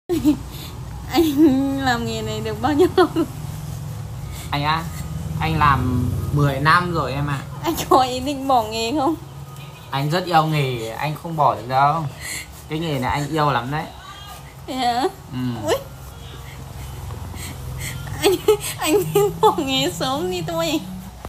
Thể loại: Câu nói Viral Việt Nam
Description: Anh làm nghề này được bao lâu rồi… bản gốc mp3 là một meme sound effect nổi tiếng, thường được sử dụng trong các video hài hước, clip troll hoặc edit trên mạng xã hội.
anh-lam-nghe-nay-duoc-bao-lau-roi-meme-sound-effect-www_tiengdong_com.mp3